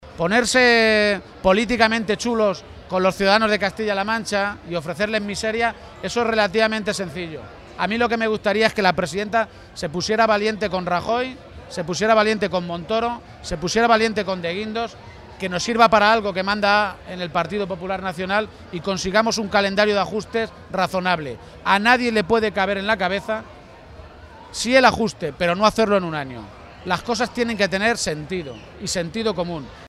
Emiliano García-Page, Secretario General del PSCM-PSOE
Respondía así García-Page a preguntas de los medios de comunicación, durante su visita a la Feria de Talavera de la Reina, sobre la posibilidad de acordar las cuentas regionales de este año, que aún no están presentadas a pesar de estar ya a mediados del mes de mayo.